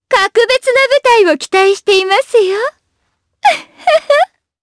Shamilla-Vox_Skill4_jp.wav